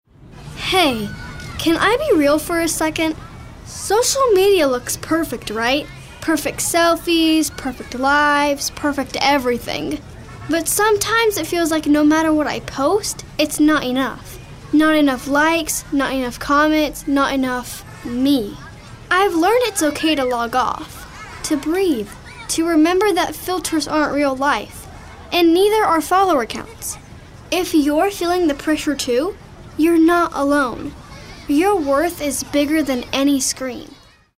anti-announcer, compelling, concerned, confessional, confident, conversational, friendly, genuine, inspirational, kid-next-door, real, sincere, teenager, thoughtful, young, younger